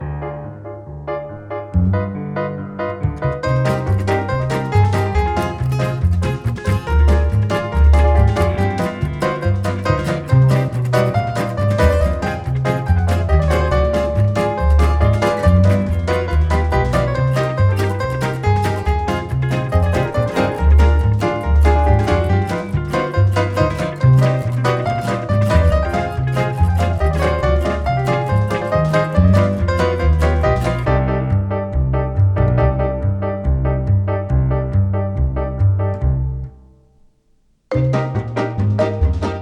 Gypsy Style Music
Wollte damit erreichen, dass es groovt, ohne dass Drums dabei sind.